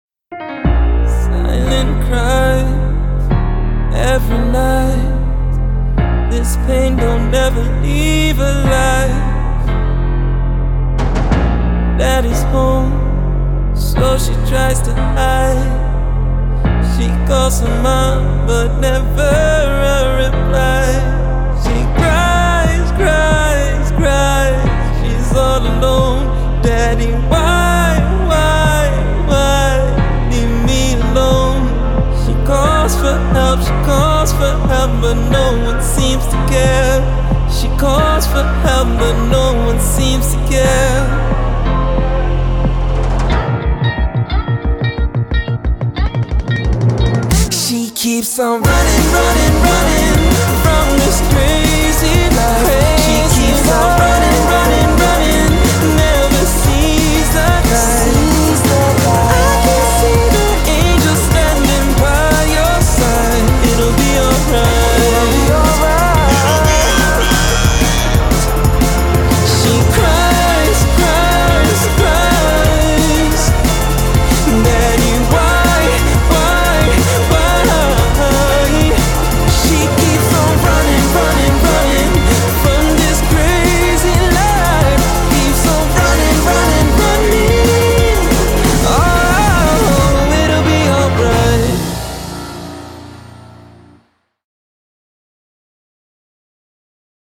BPM90-180
Audio QualityPerfect (High Quality)
Comments[EMOTIONAL D'N'B]